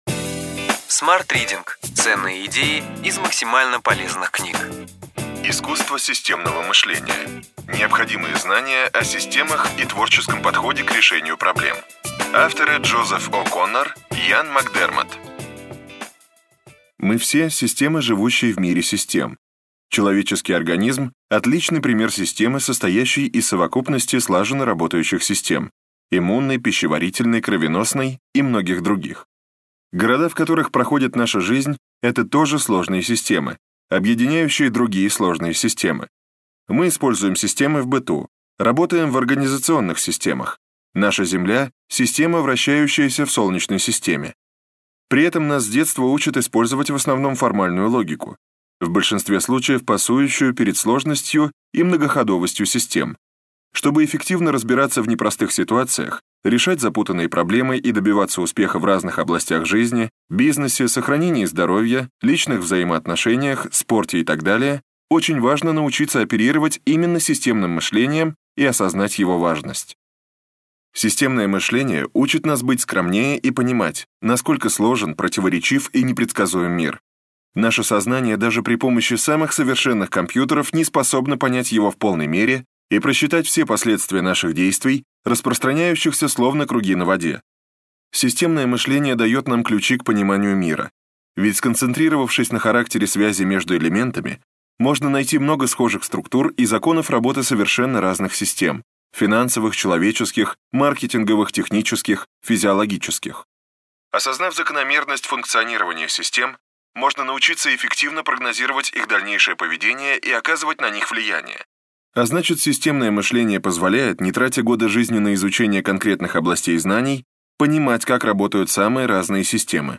Аудиокнига Ключевые идеи книги: Искусство системного мышления.